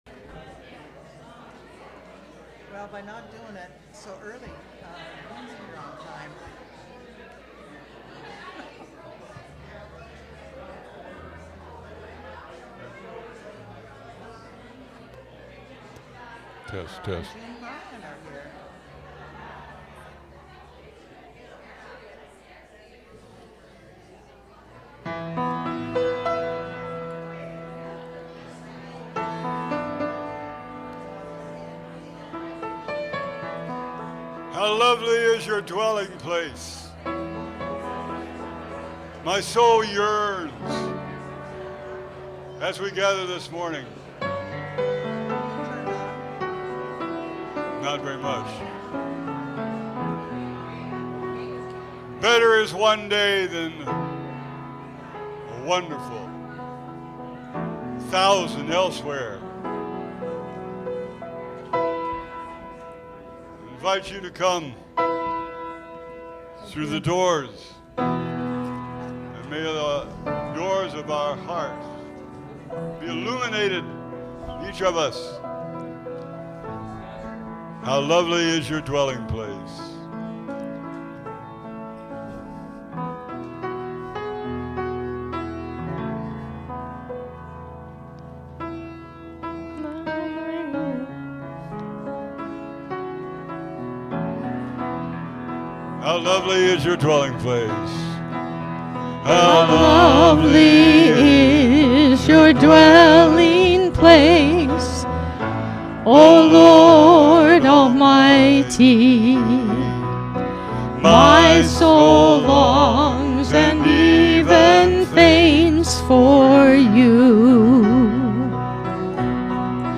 Worship_-October-8_-2023-voice-only..mp3